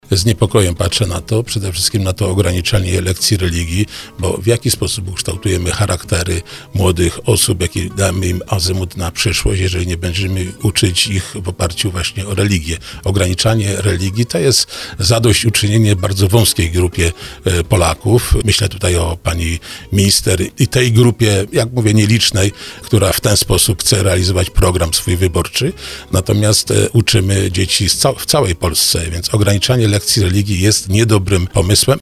Te zmiany w programie Słowo za Słowo komentował wicemarszałek województwa małopolskiego. Ryszard Pagacz. W opinii szefa małopolskich struktur PiS, ograniczenie lekcji religii w szkołach jest błędem.